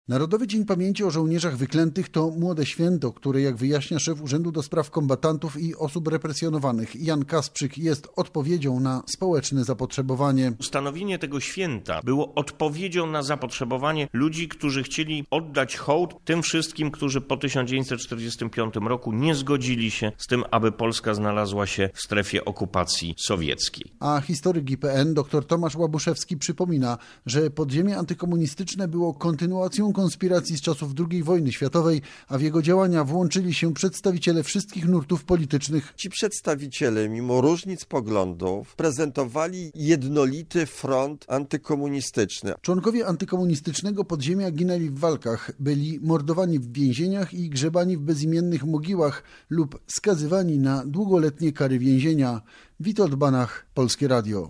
spec-historycy-o-zolnierzach-wykletych.mp3